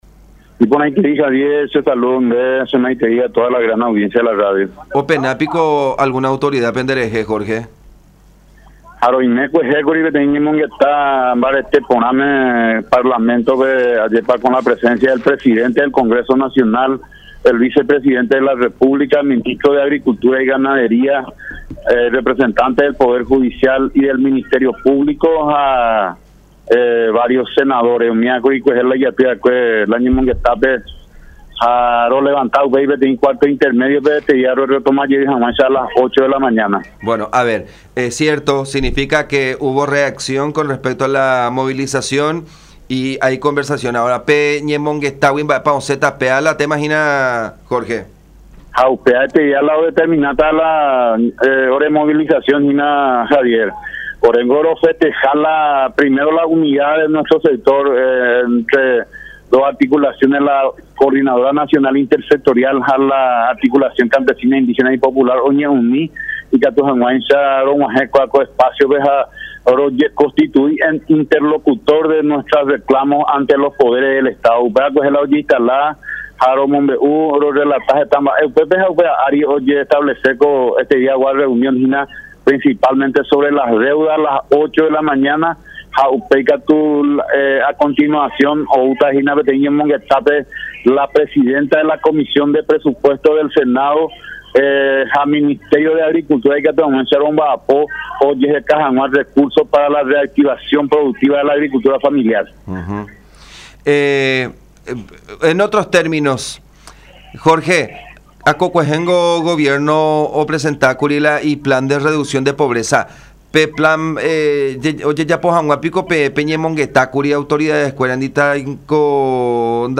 en conversación con La Unión.